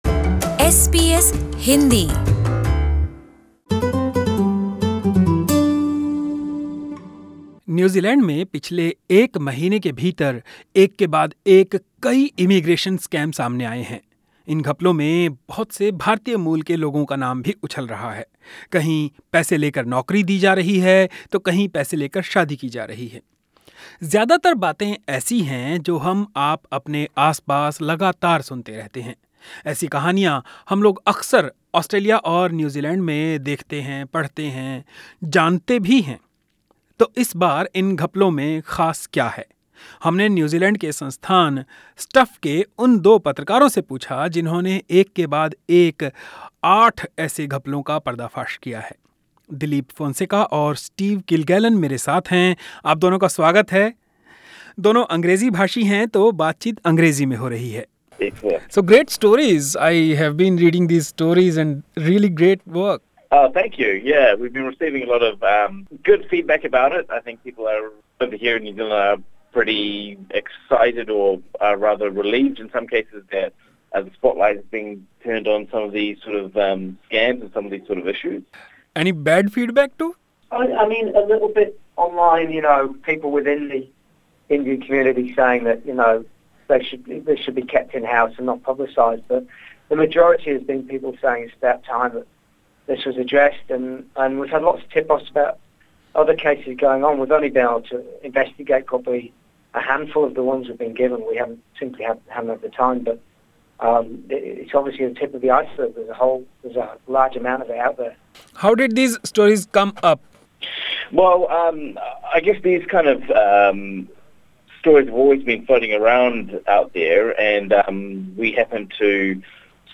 हमने न्यू जीलैंड के संस्थान स्टफ के उन दो पत्रकारों से पूछा जिन्होंने एक के बाद एक आठ ऐसे घपलों का पर्दाफाश किया है.